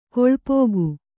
♪ koḷpōgu